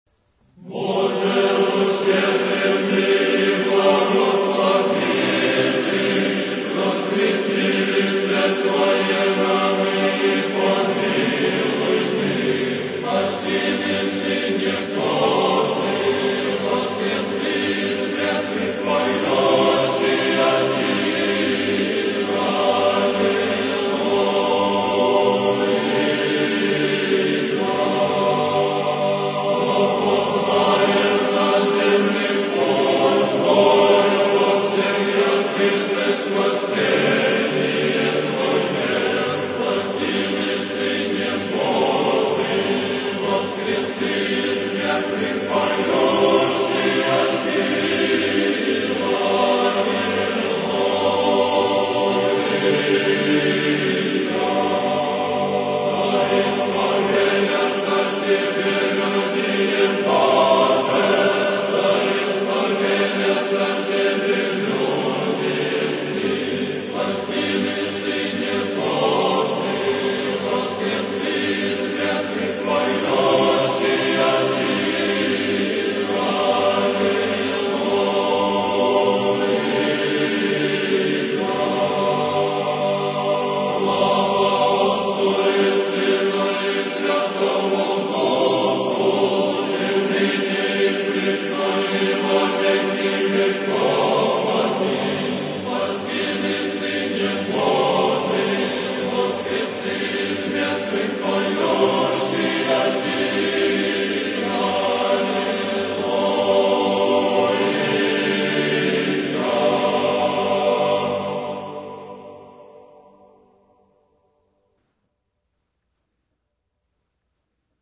Духовная музыка